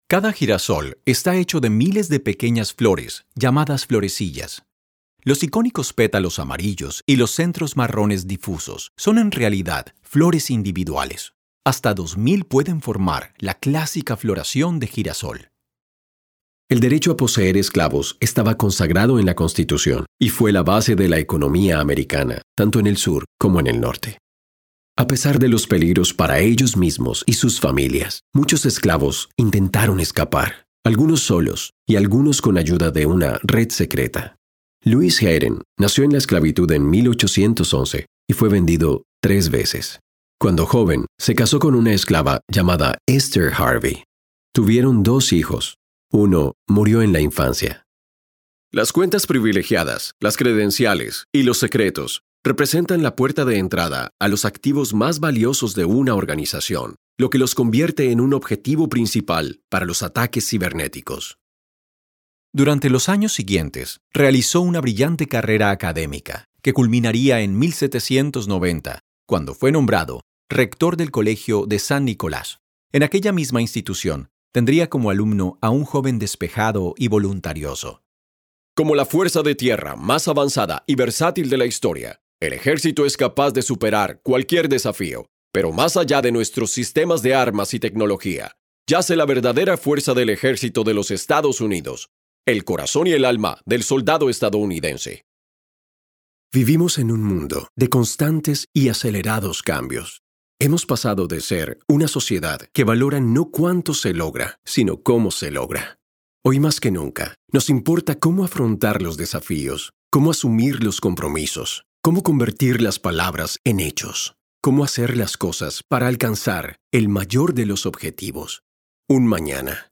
Spanish (Colombia)
English (Latin American Accent)
Adult (30-50) | Yng Adult (18-29)